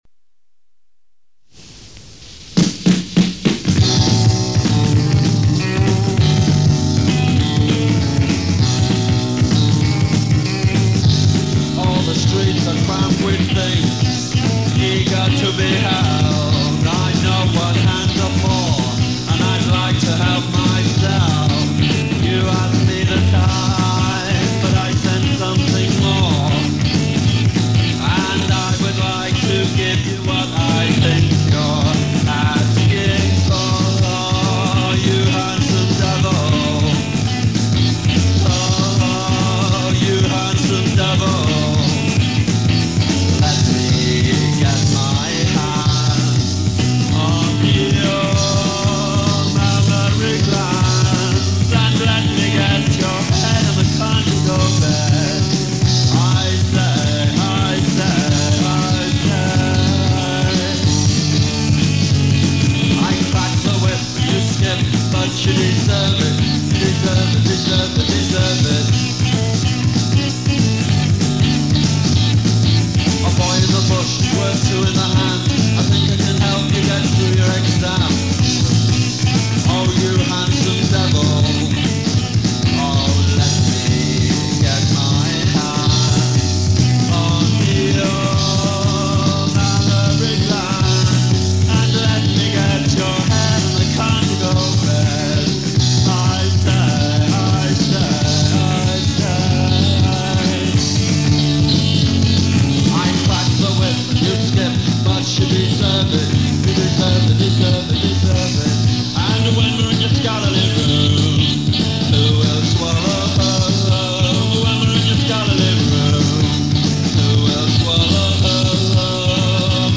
early demo